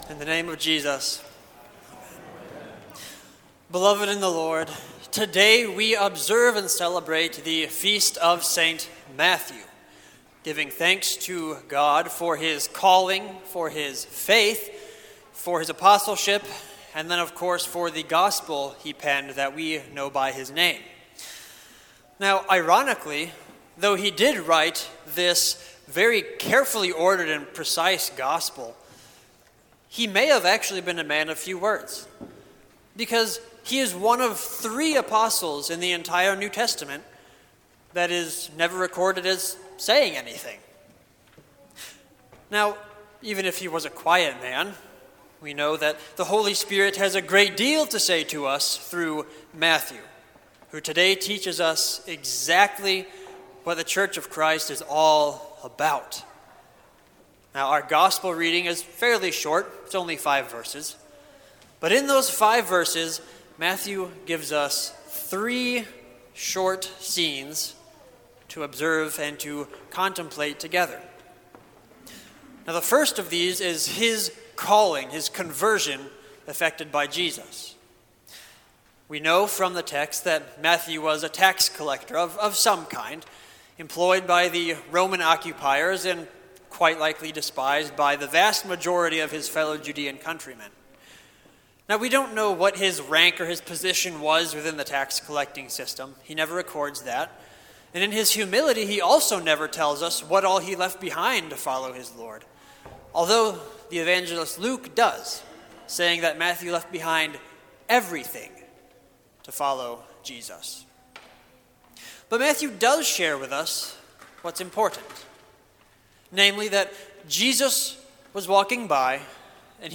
Sermon for St. Matthew, Apostle and Evangelist